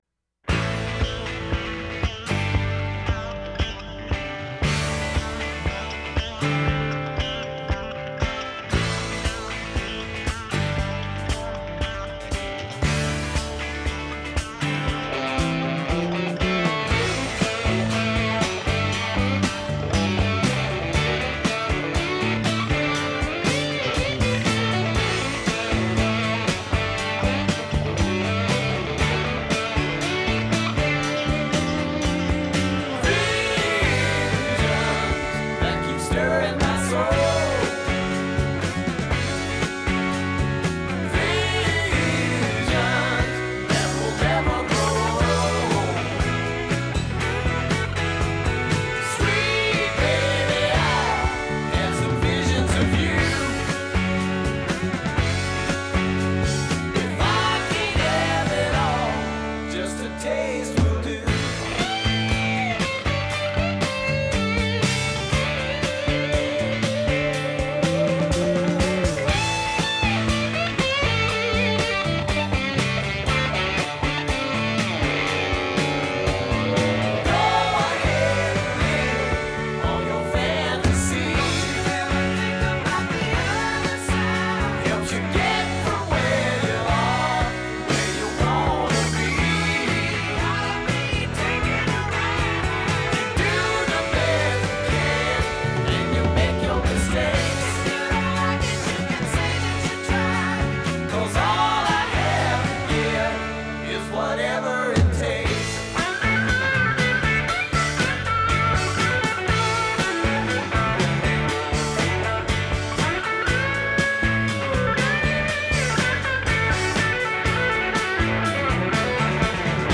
It was the most up-tempo track of the whole album.